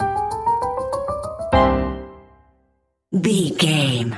Aeolian/Minor
flute
oboe
strings
circus
goofy
comical
cheerful
perky
Light hearted
quirky